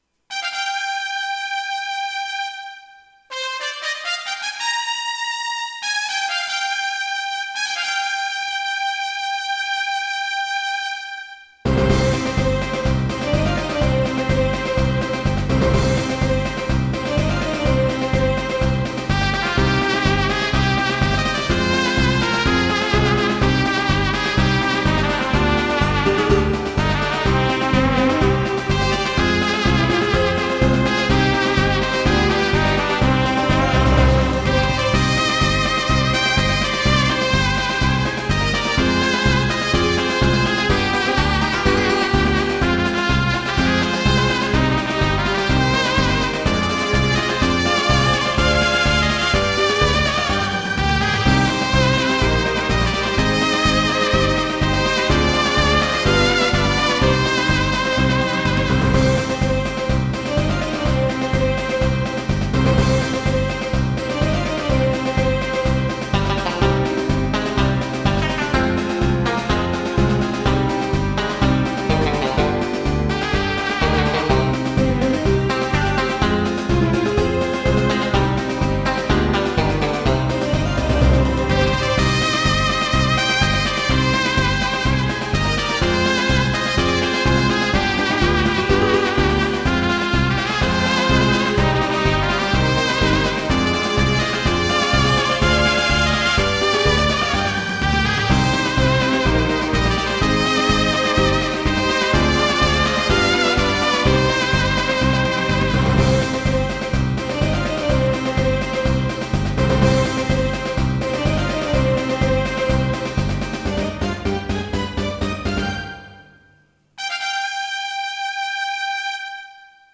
なおテンポは個人的にしっくりくる速度に変えています(原曲よりやや速め)。